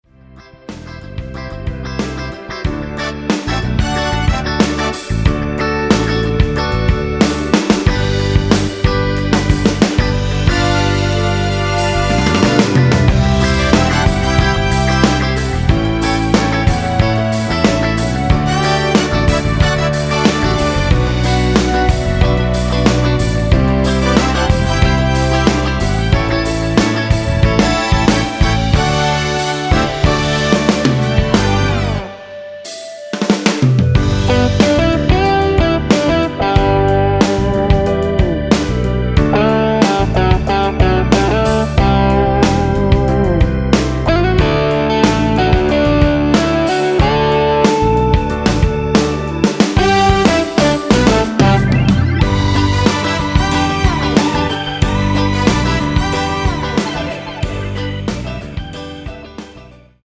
원키 멜로디 포함된 MR입니다.(미리듣기 확인)
멜로디 MR이라고 합니다.
앞부분30초, 뒷부분30초씩 편집해서 올려 드리고 있습니다.